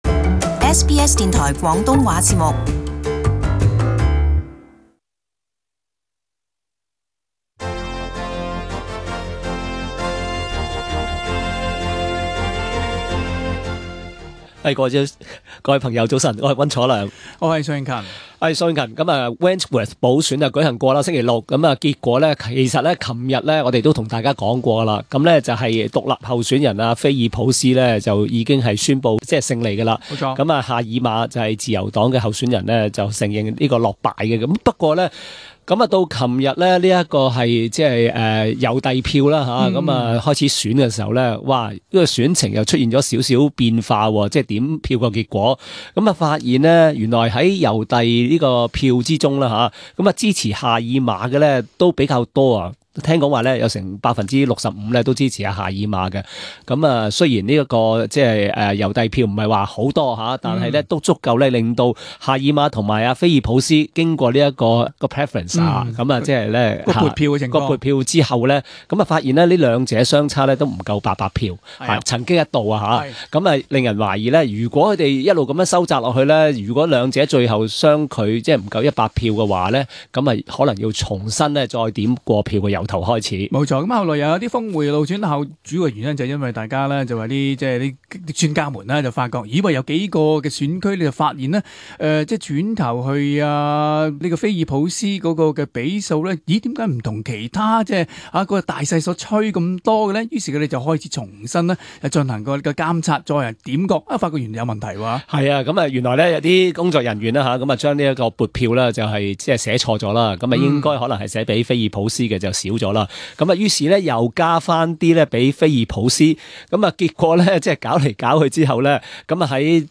【時事報導】 點票結果峰回路轉 菲爾普斯勝望仍濃